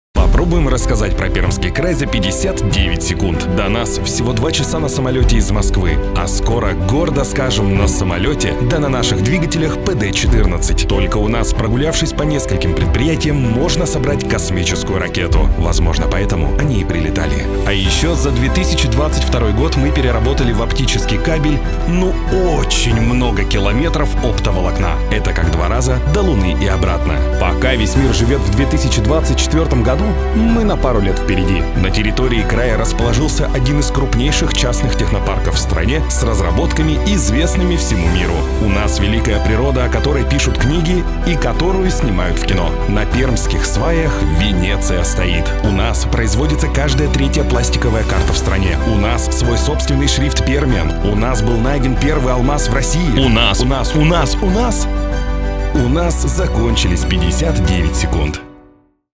Тракт: Микрофон: Recording Tools MC-900 Пред: dbx-376 Карта: UA Apollo Twin
Демо-запись №2 Скачать